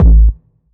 GS Phat Kicks 018.wav